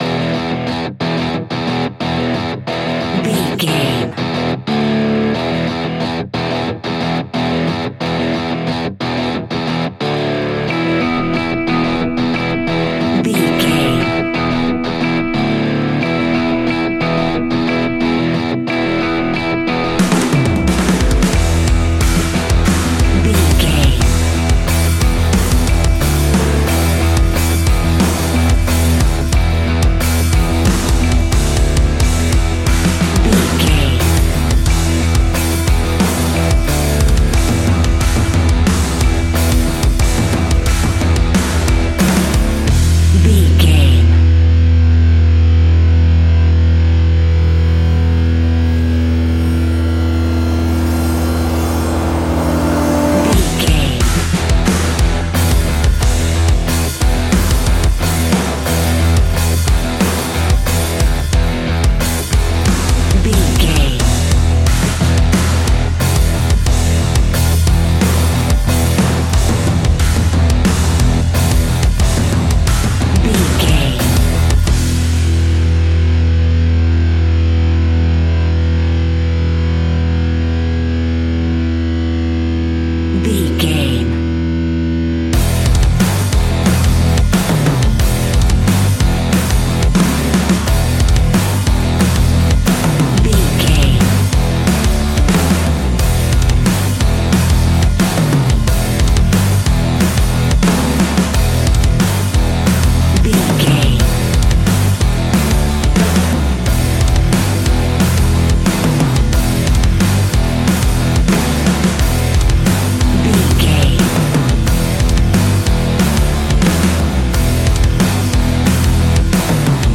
Ionian/Major
E♭
hard rock
guitars
instrumentals